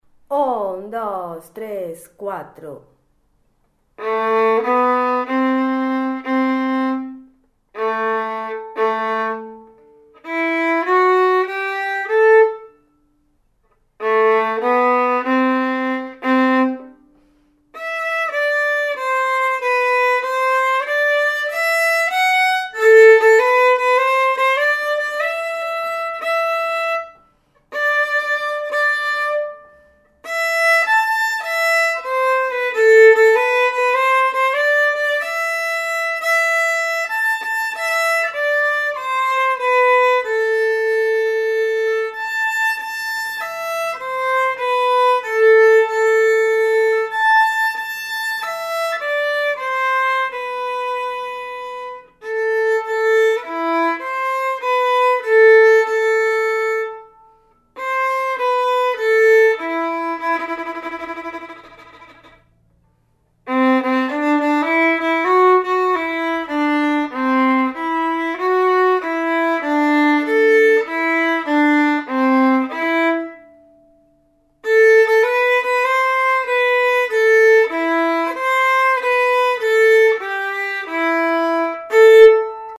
Los audios de las tres voces